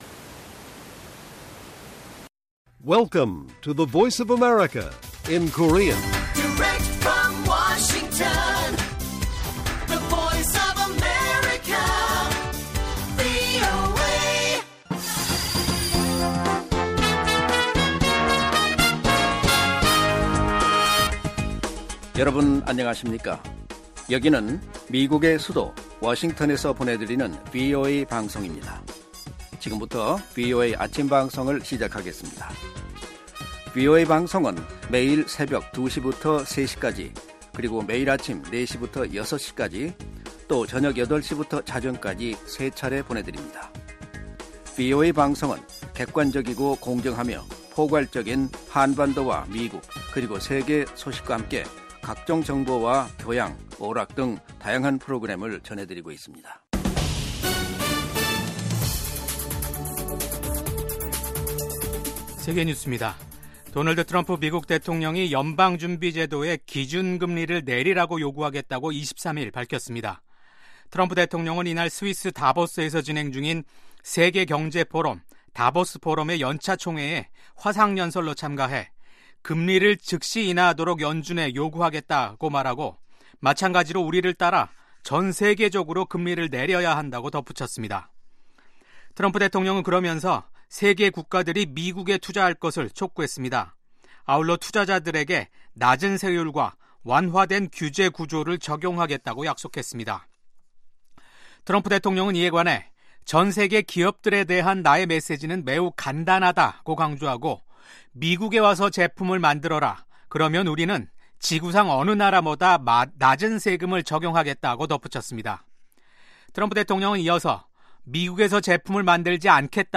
세계 뉴스와 함께 미국의 모든 것을 소개하는 '생방송 여기는 워싱턴입니다', 2025년 1월 24일 아침 방송입니다. 미국 정부가 남부 국경에 현역 군인 1천500명을 배치합니다. 러시아가 우크라이나 전쟁 종식에 합의하지 않으면 관세를 부과할 것이라고 도널드 트럼프 미국 대통령이 경고했습니다. 미국에서 도널드 트럼프 2기 행정부가 출범한 가운데 독일과 프랑스 정상이 회담을 갖고 유럽의 더 공고한 단합을 촉구했습니다.